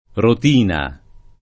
vai all'elenco alfabetico delle voci ingrandisci il carattere 100% rimpicciolisci il carattere stampa invia tramite posta elettronica codividi su Facebook rotina [ rot & na ] (meglio che ruotina ) s. f. — cfr. ruota